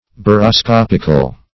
Search Result for " baroscopical" : The Collaborative International Dictionary of English v.0.48: Baroscopic \Bar`o*scop"ic\, Baroscopical \Bar`o*scop"ic*al\, a. Pertaining to, or determined by, the baroscope.